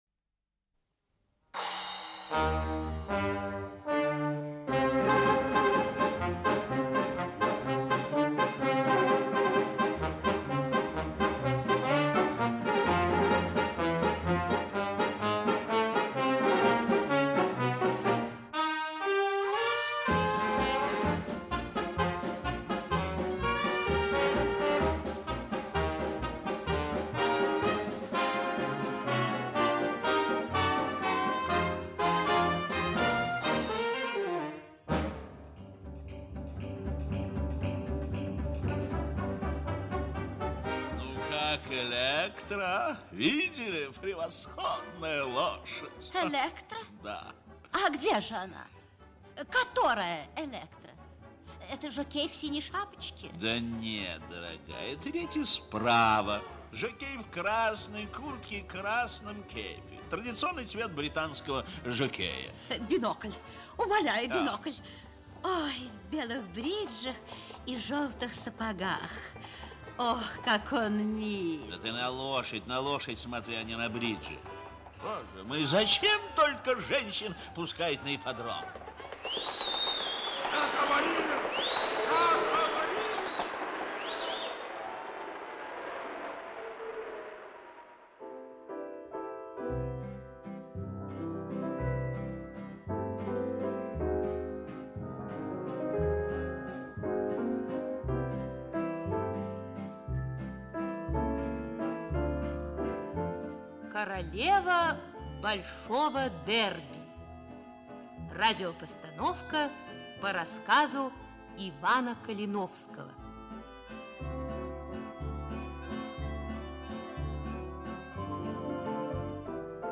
Аудиокнига Королева большого дерби | Библиотека аудиокниг
Aудиокнига Королева большого дерби Автор Иван Калиновский Читает аудиокнигу Актерский коллектив.